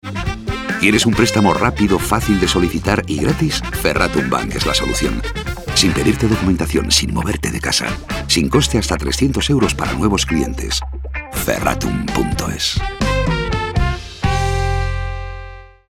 Como en campañas anteriores la planificación se ha desarrollado en medios de comunicación Offline, en concreto radio y prensa.